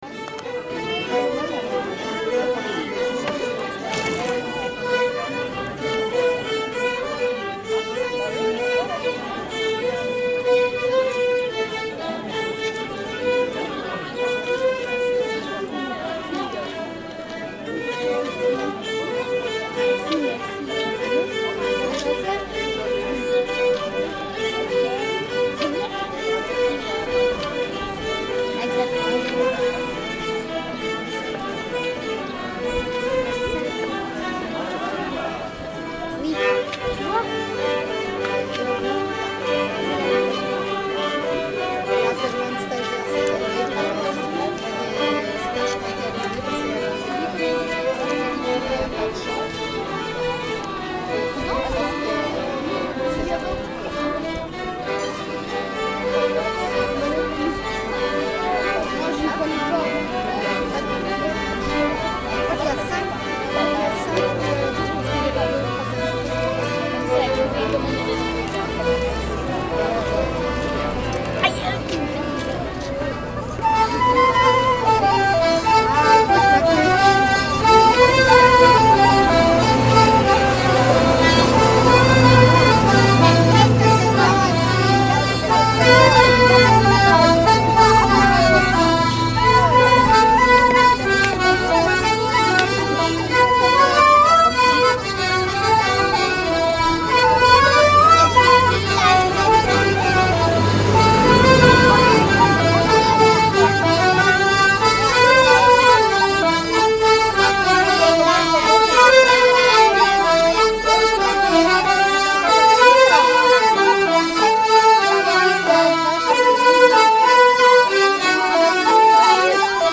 06_tours-violons-flutes-accordeons.mp3